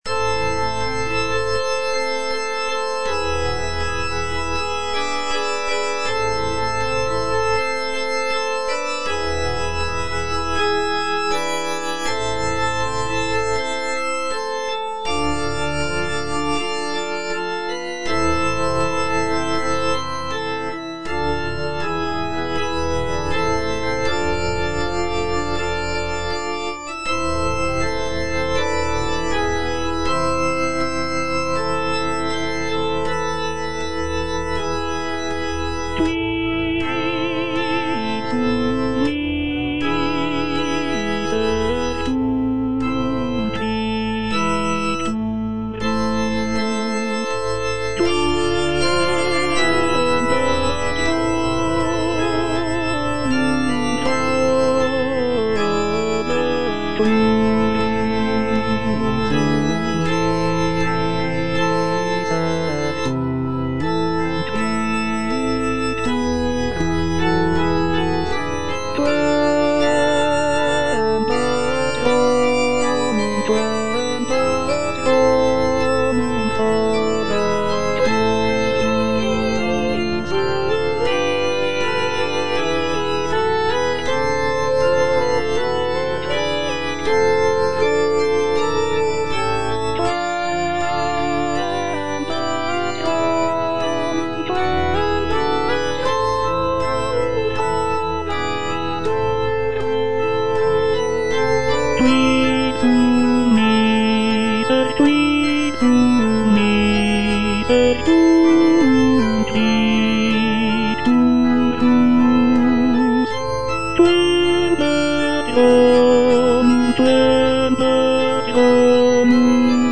Tenor (Voice with metronome) Ads stop
is a sacred choral work rooted in his Christian faith.